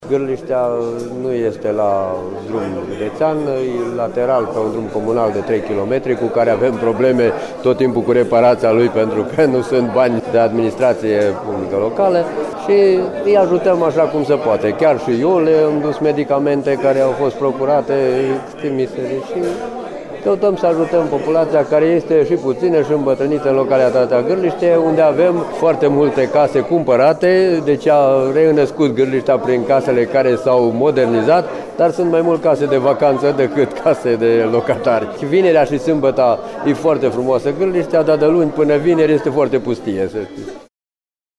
Cetăţenii din satul Gârlişte se confruntă şi cu lipsa de medicamente, cu atât mai mult cu cât populaţia este îmbătrânită, spune primarul Dumitru Crăciun. Acesta crede că medicii nu ajung în Gârlişte şi din cauza drumului care crează mereu probleme.